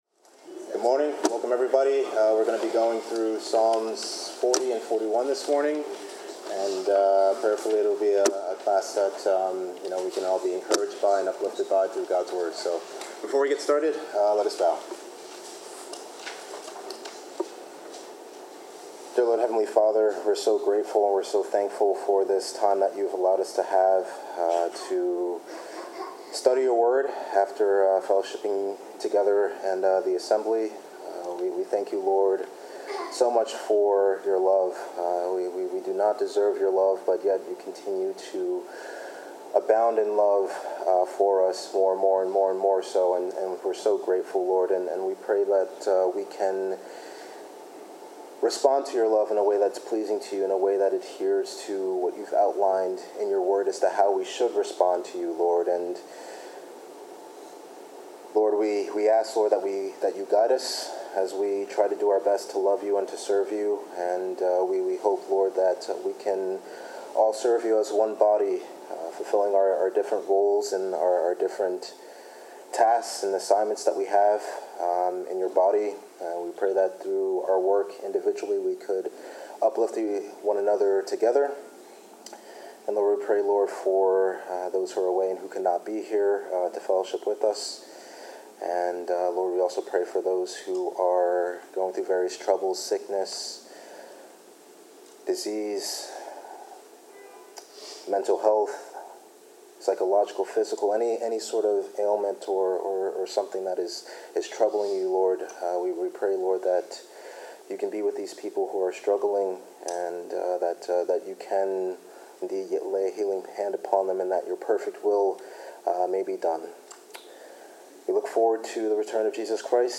Bible class: Psalms 40-41
Passage: Psalms 40-41 Service Type: Bible Class